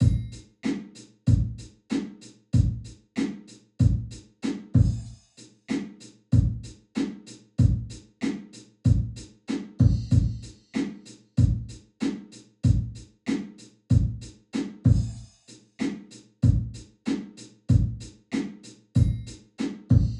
The lower part of the stairway has a sloping ceiling which gives a bit of a flutter echo.
Now 100% wet with the ‘Stairway 1’ IR:
I like the nice low-end thump to that particular reverb
ir-example-drum-loop-100-wet-stairway-1.wav